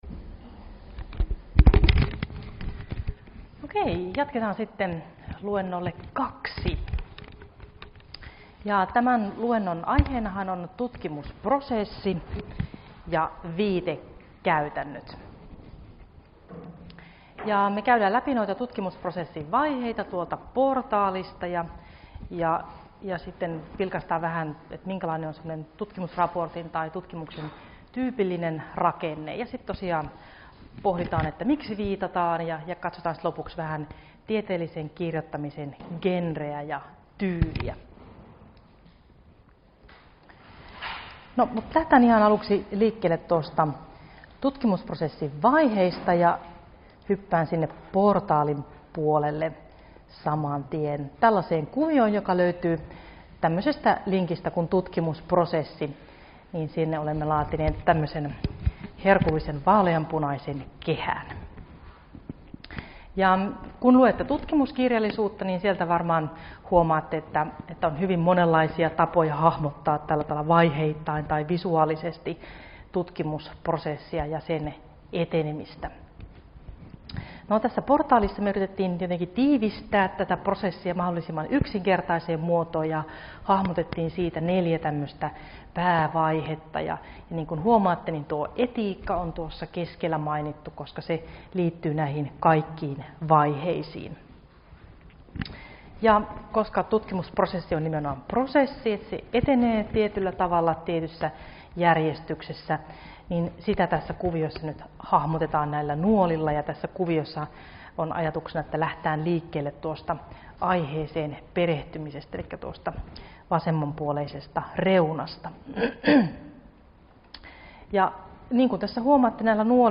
Luento 2 - Tutkimusprosessi, raportointi ja viitekäytännöt — Moniviestin